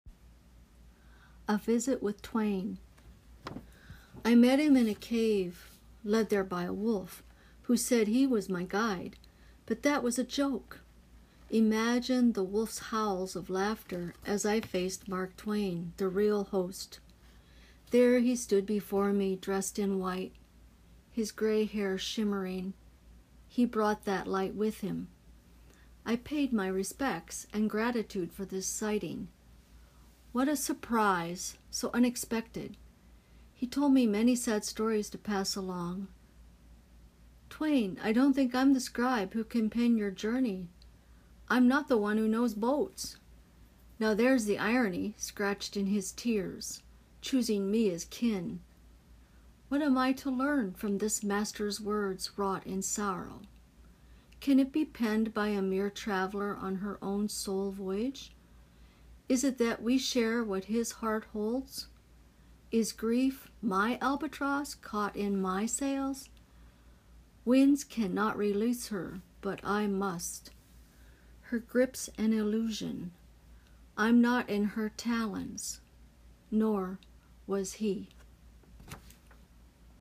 And once again, you’ve got a soothing voice.